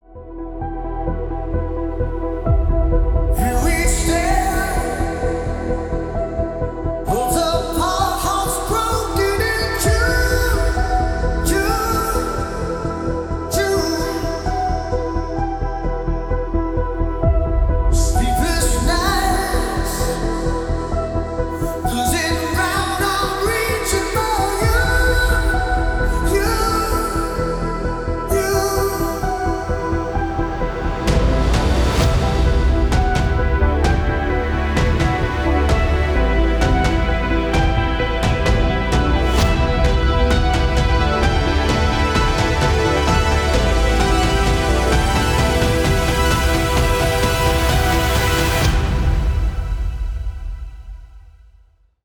• Качество: 320, Stereo
remix
атмосферные
красивый мужской вокал
synthwave
эпичные